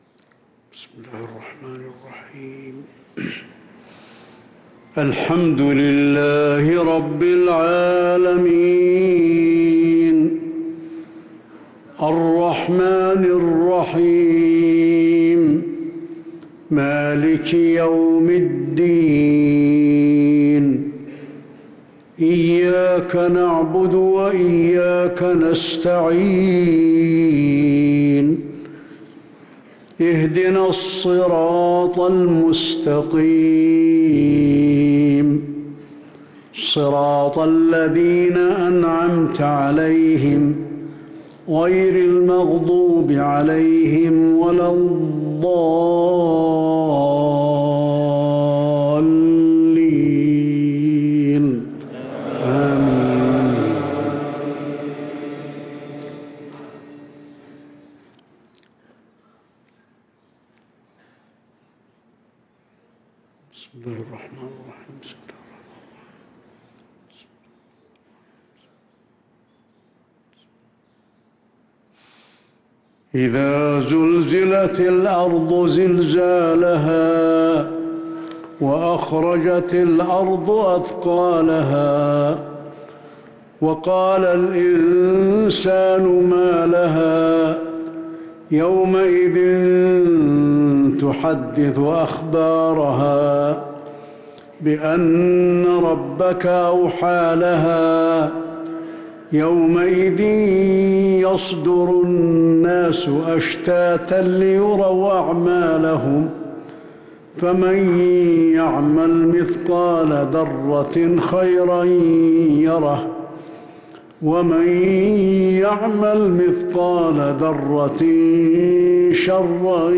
صلاة المغرب للقارئ علي الحذيفي 14 ربيع الأول 1444 هـ
تِلَاوَات الْحَرَمَيْن .